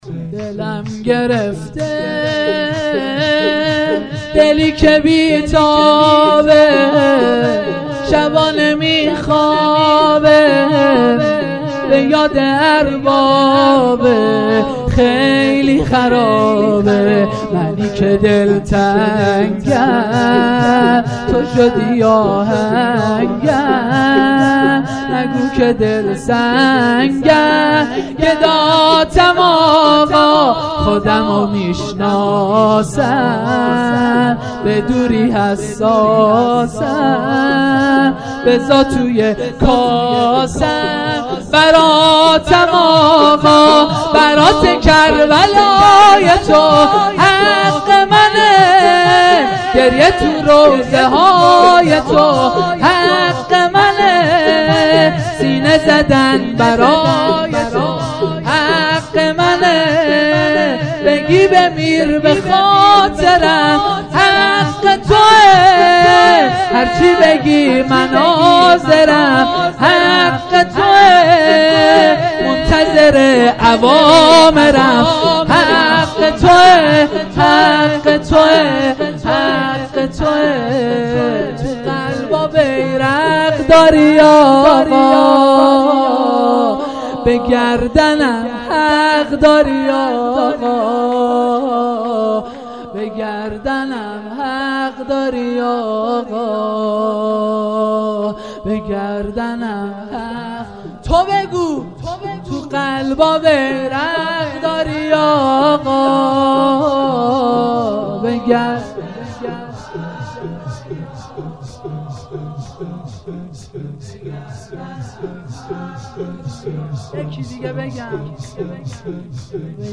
جلسه مذهبی زیارت آل یاسین باغشهر اسلامیه
شور 04